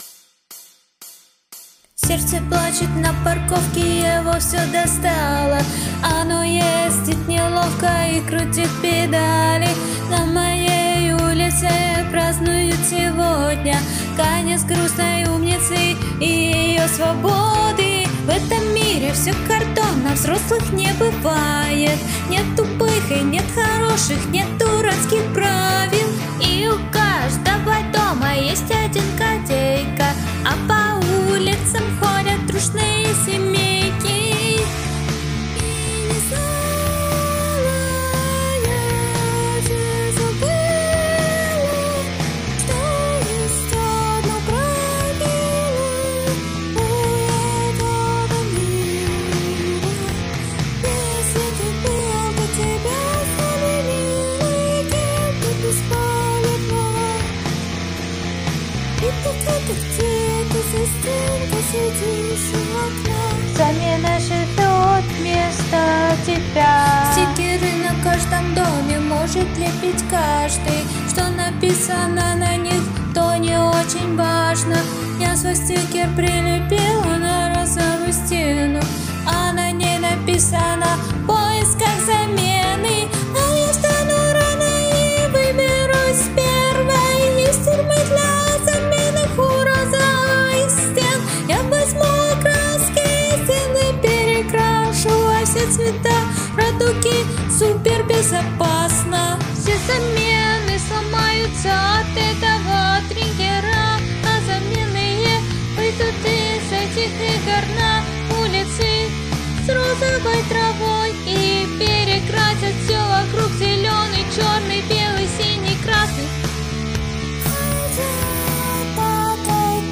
сделала несколько записей а потом нашла в бандлабе бесплатные минуса, и нашла под песню, пришлось подкорректировать изначальный текст, но несущественно (тут изначальный текст, в песне переставлены четверостишия)
Короче , песня с музыкой 😭💔